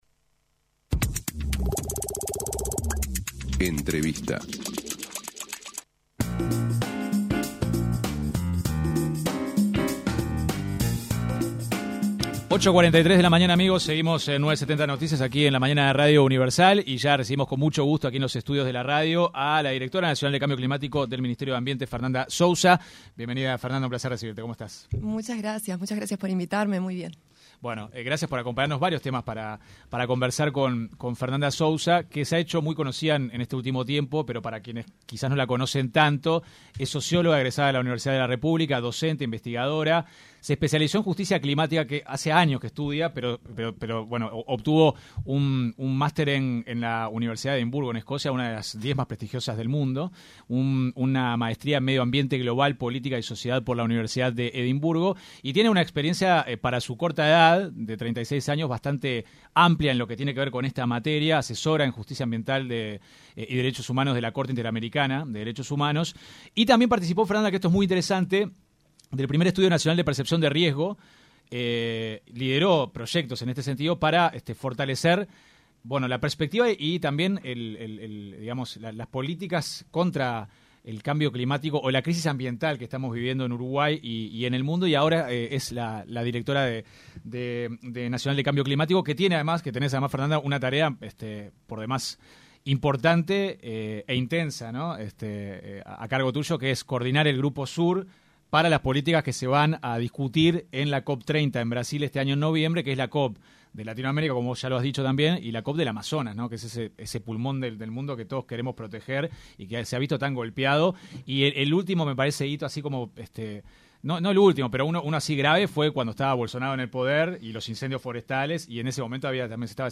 La directora nacional de Cambio Climático, María Fernanda Souza, se refirió en diálogo con 970 Noticias, al grupo Sur, el cual negocia Uruguay los temas de este rubro.